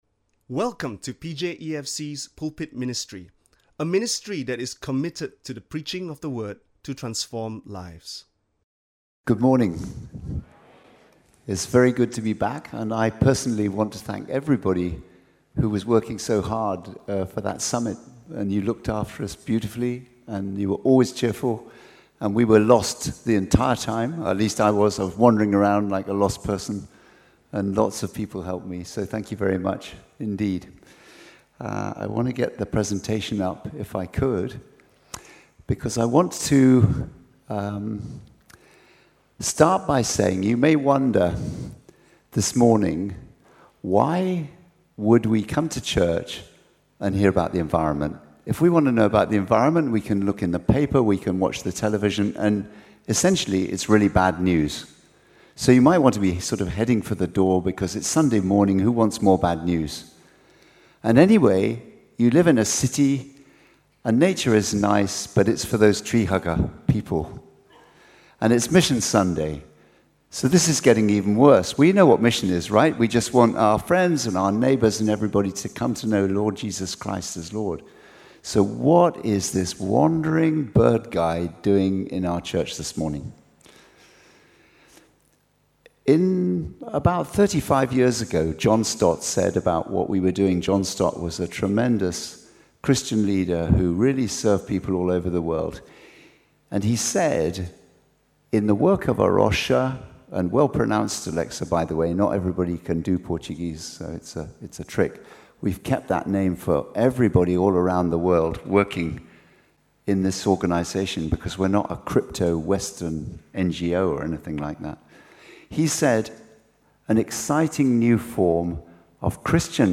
Listen to Sermon Only
In conjunction with Mission Sunday, this is a stand alone sermon.